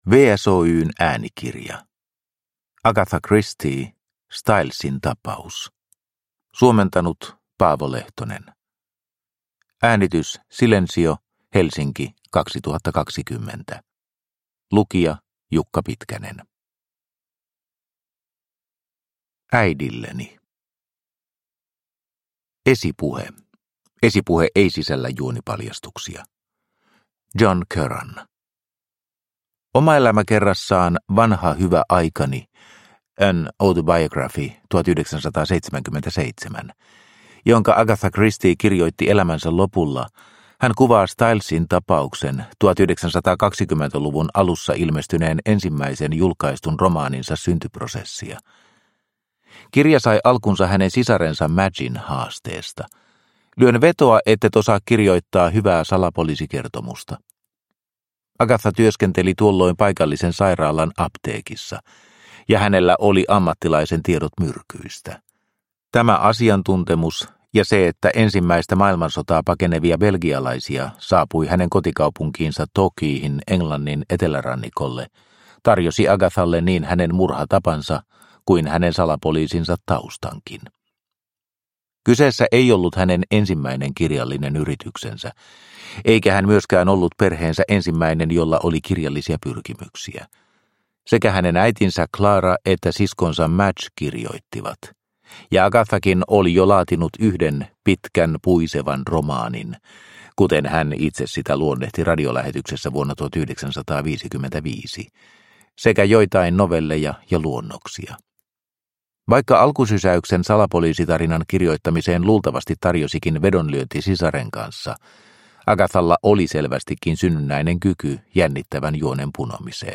Stylesin tapaus – Ljudbok – Laddas ner